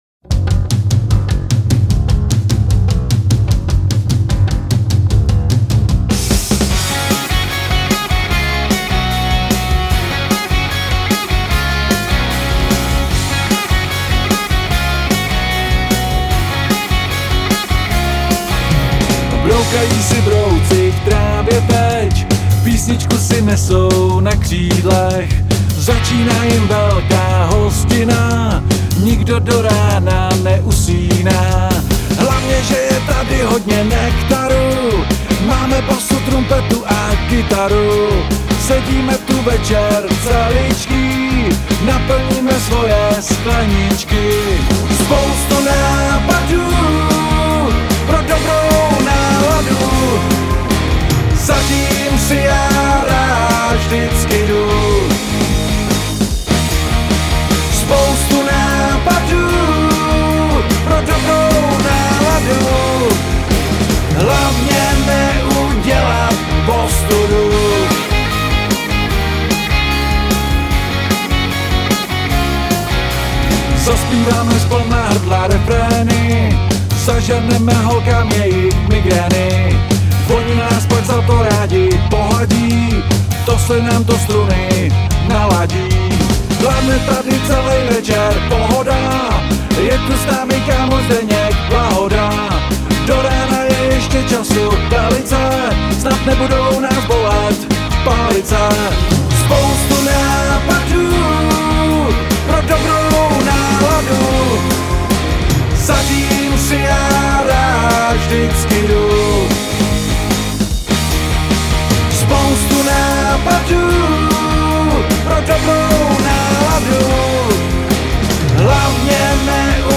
obsahuje 13 rockových pecek s chytlavými českými texty.